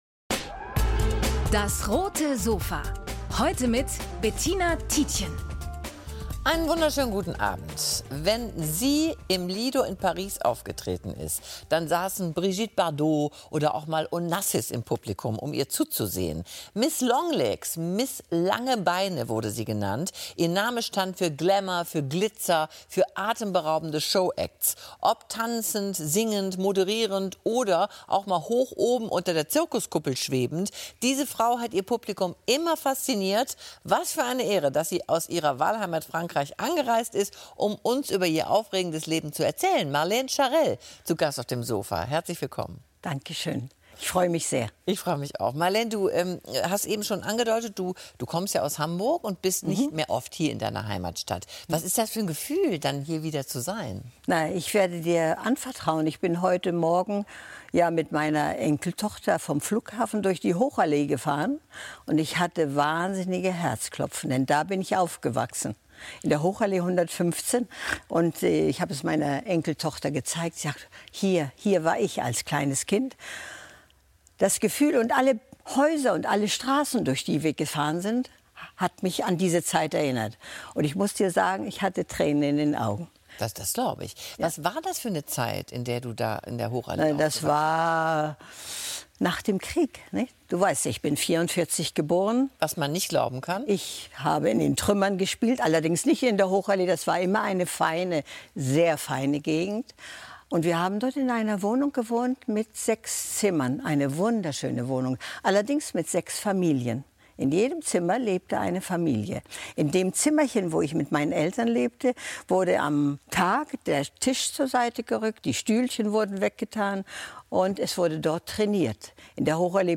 Sängerin Marlène Charell im Talk mit Bettina Tietjen ~ DAS! - täglich ein Interview Podcast